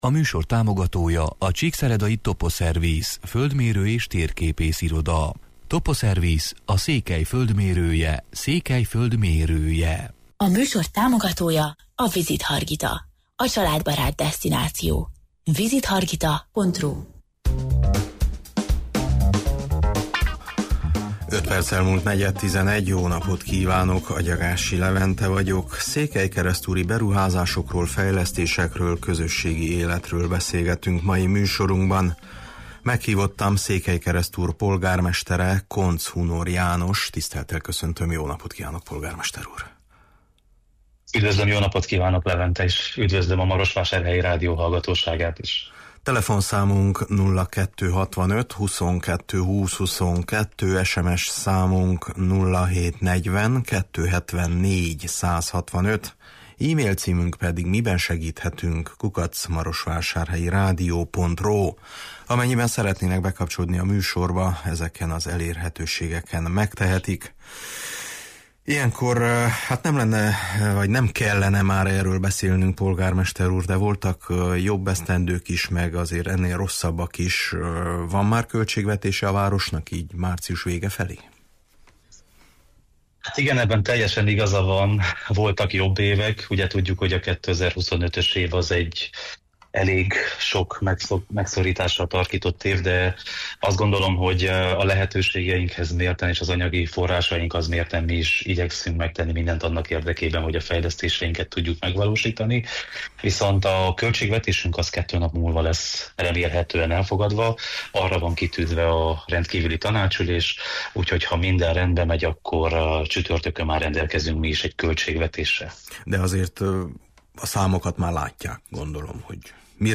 Meghívottam a város polgármestere, Koncz Hunor János: